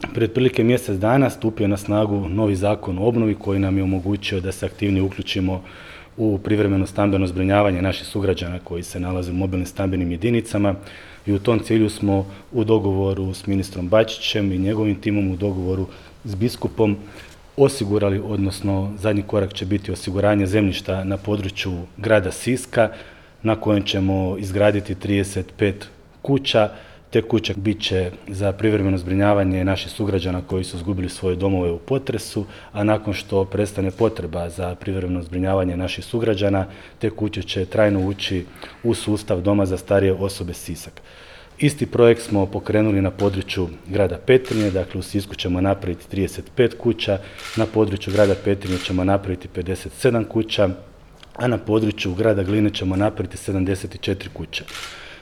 Potpisivanju je nazočio i župan Ivan Celjak, koji je pojasnio i razloge ove zamjene